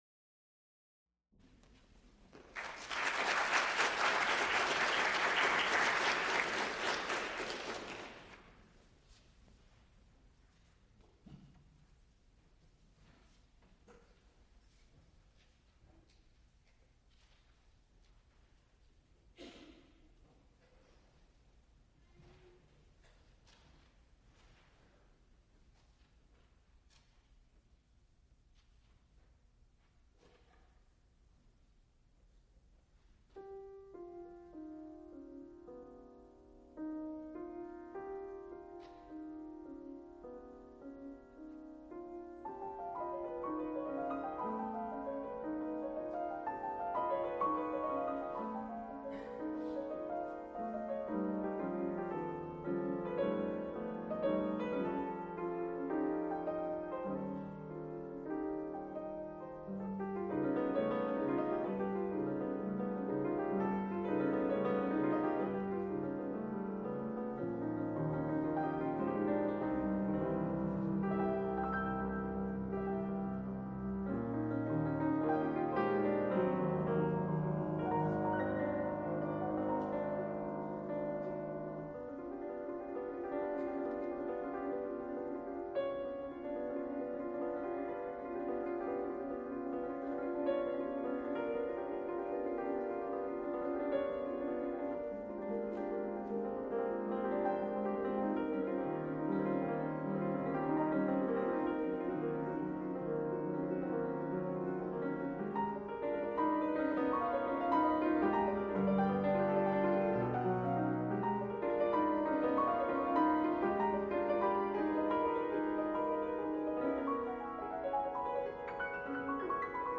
piano., Recorded live October 24, 1978, Frick Fine Arts Auditorium, University of Pittsburgh.
Type sound recording-musical Genre musical performances